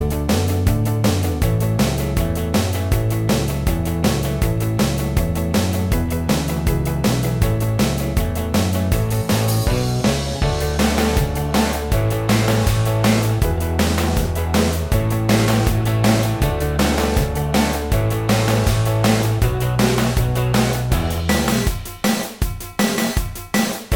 Minus Solo Guitar Pop (1970s) 3:10 Buy £1.50